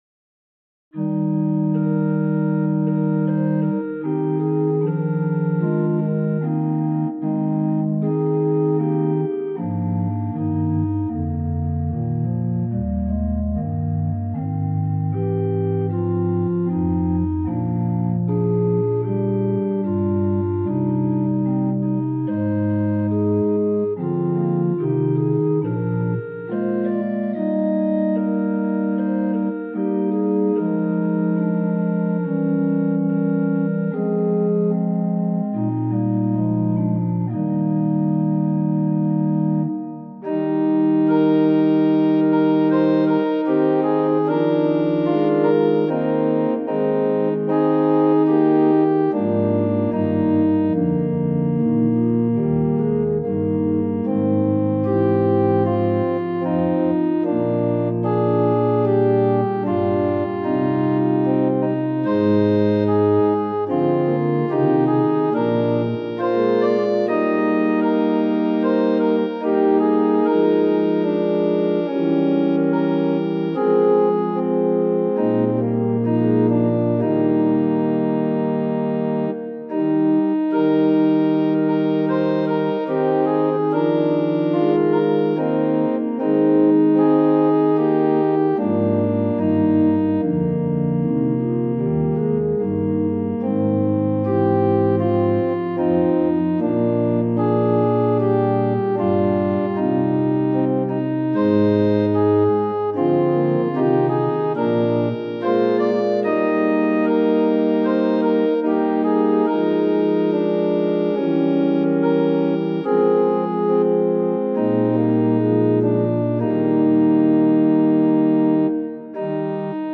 ♪賛美用オルガン伴奏音源：
・柔らかい音色部分は前奏です
・はっきりした音色になったら歌い始めます
・節により音色が変わる場合があります
・間奏は含まれていません
Tonality = Es
Pitch = 440
Temperament = Equal